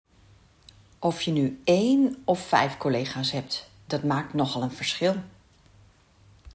Bij zin B gaat dat wel goed.
Je zegt daarom [un], ook wel de stomme /e/ of sjwa genoemd.